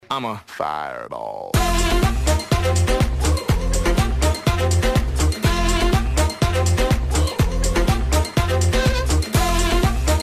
Sound Buttons: Sound Buttons View : FireBall 1
fire-ball.mp3